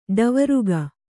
♪ ḍavaruga